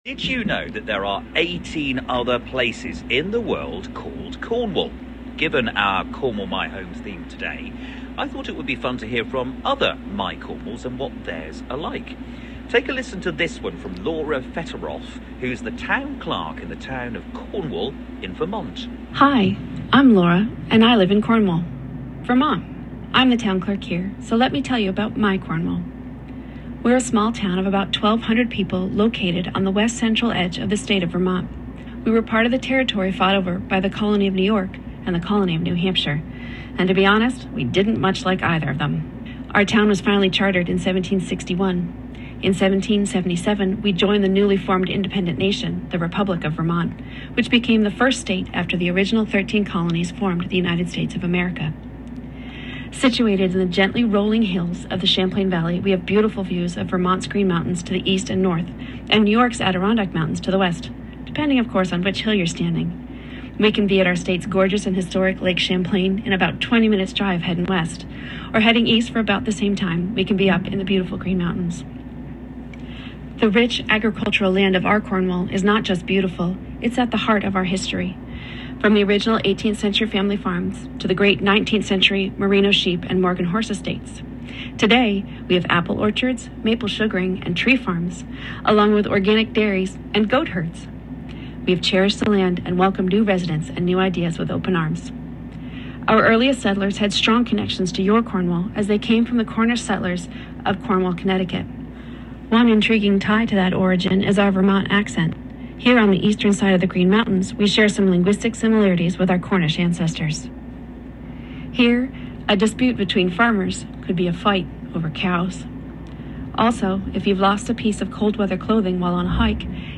Our Cornwall was featured on BBC Radio Cornwall in the UK!